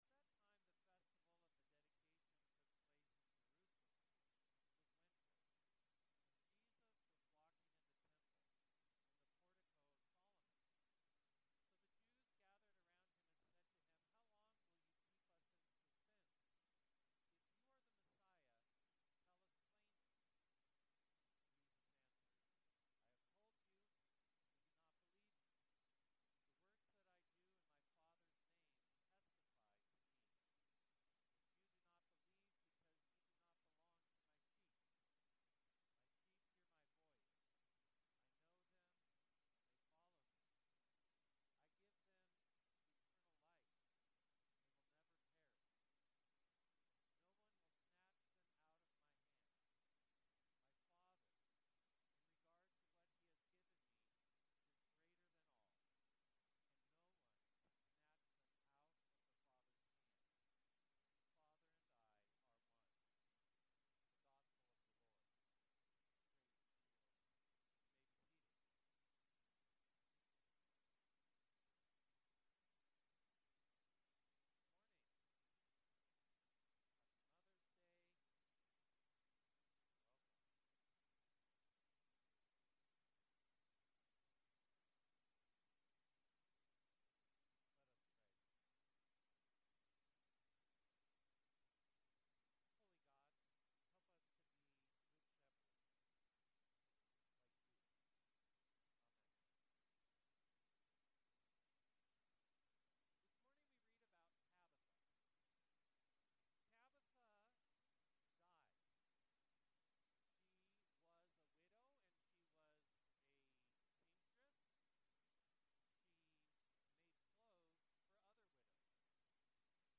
Sermon 05.11.25